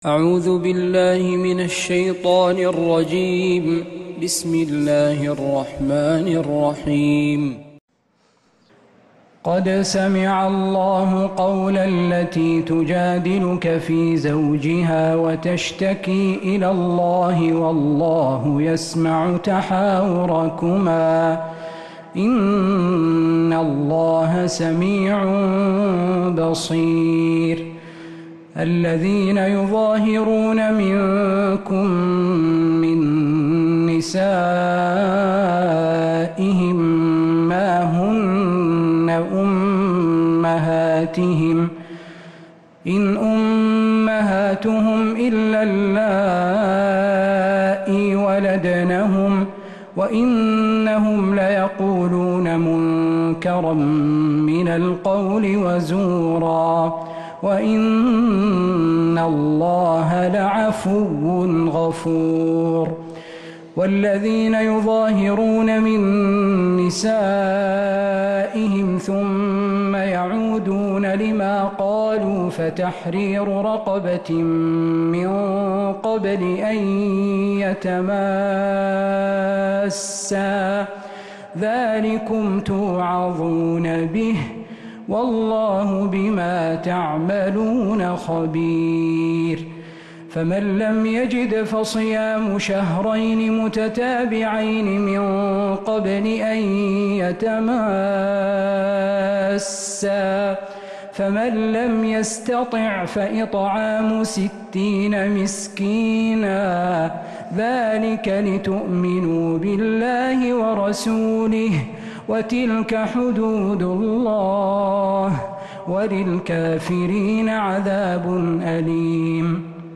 سورة المجادلة من تراويح الحرم النبوي